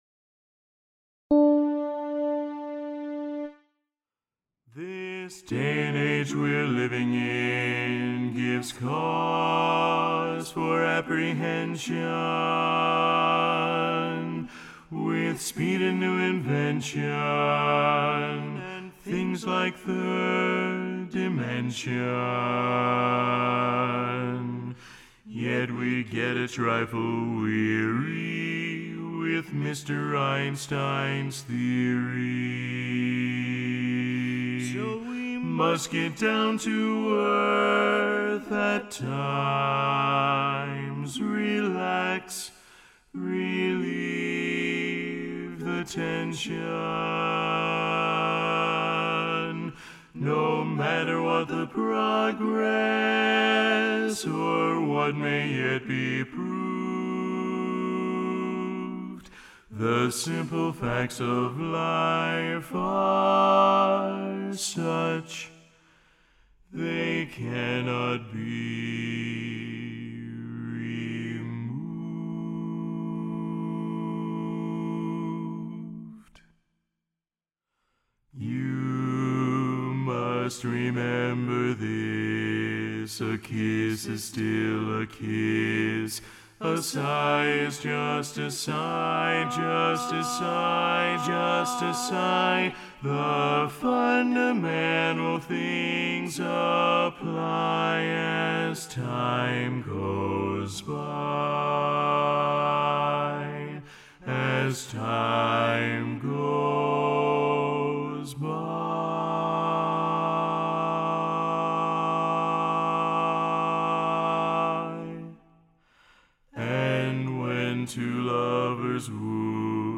Kanawha Kordsmen (chorus)
D Major
Bari